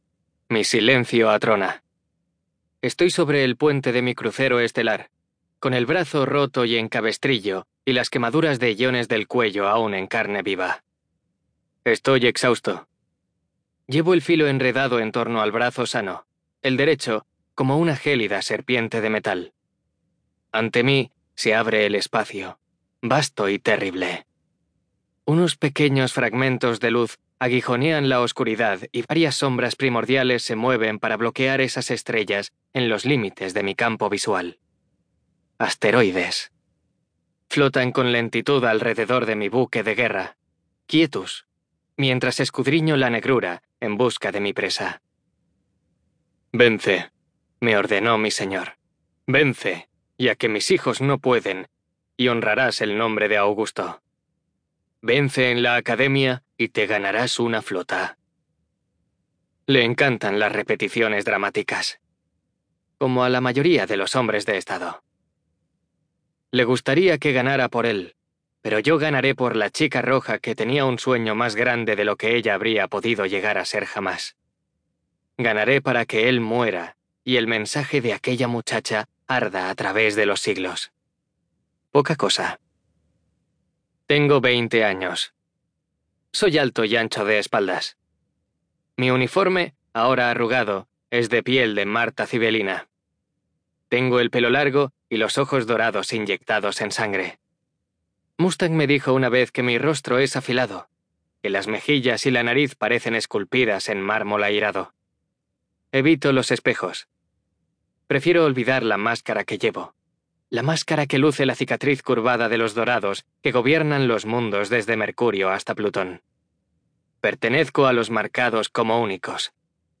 TIPO: Audiolibro CLIENTE: Audible Inc. ESTUDIO: Eclair Barcelona